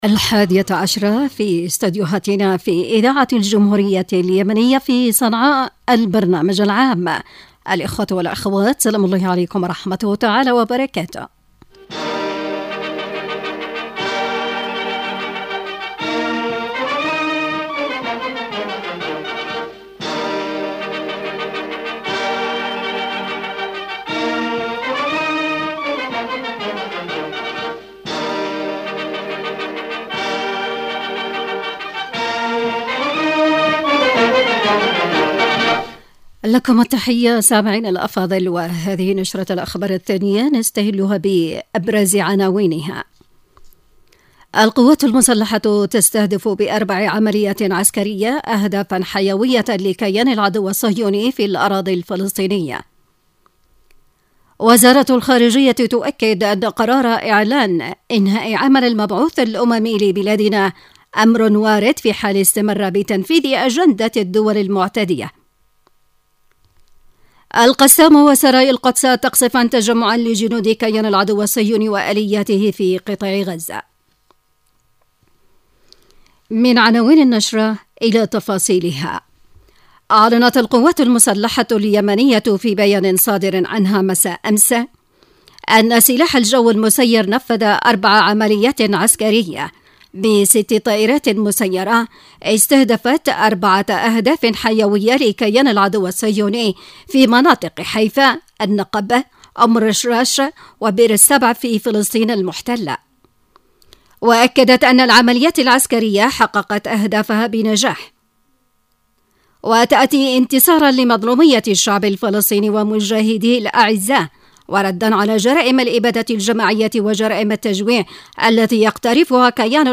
نشرة الحادية عشرة